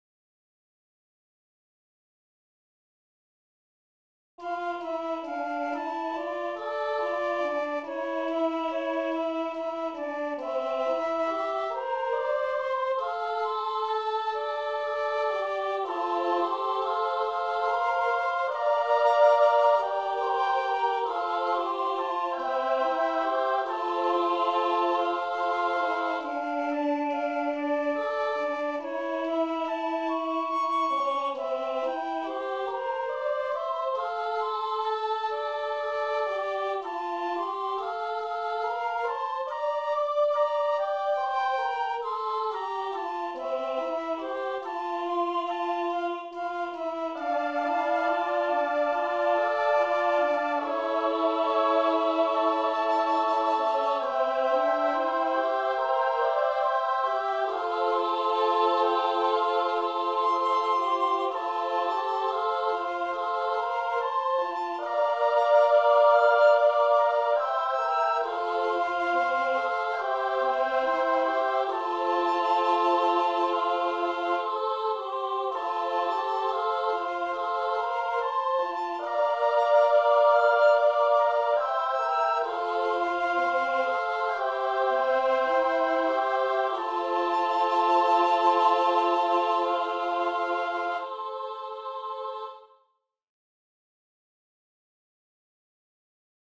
Taivas-veden-pinnalla-laulu-ja-huilu.mp3